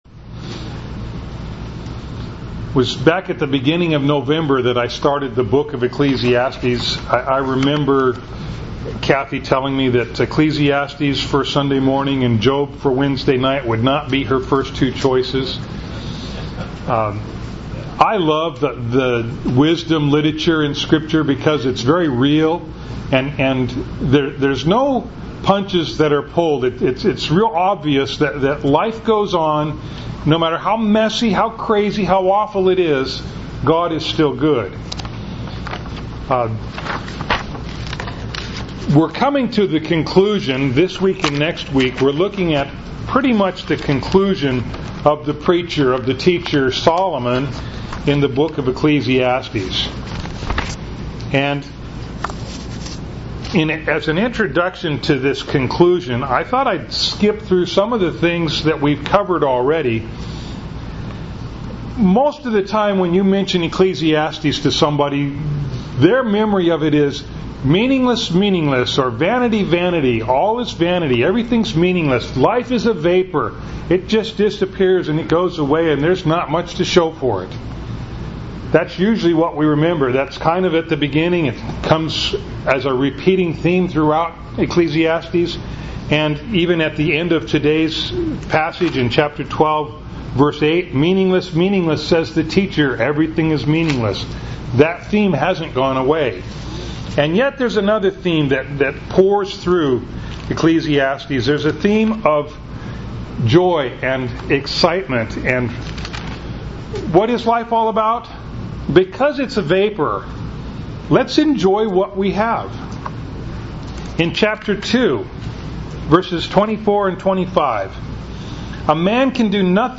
Ecclesiastes 11:7-12:8 Service Type: Sunday Morning Bible Text